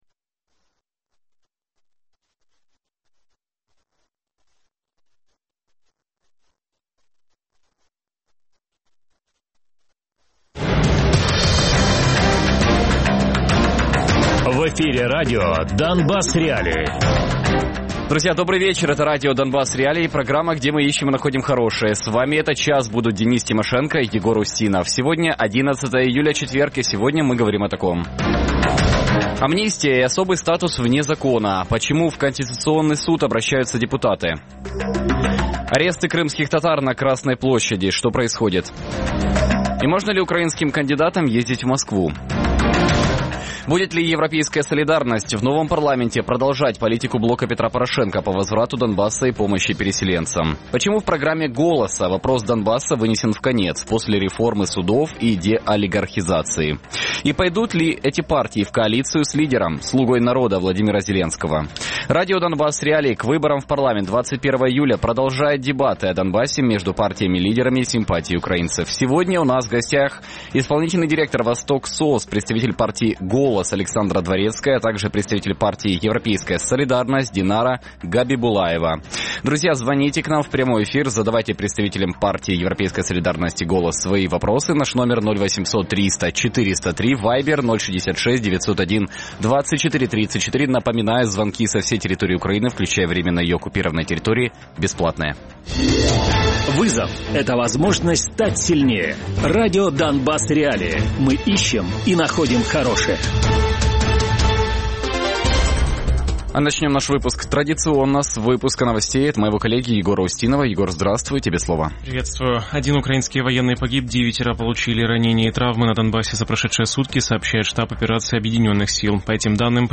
Донбас.Реалії | Дебати по Донбасу. «Голос» vs «Європейська Солідарність»
Радіопрограма «Донбас.Реалії» - у будні з 17:00 до 18:00. Без агресії і перебільшення. 60 хвилин про найважливіше для Донецької і Луганської областей.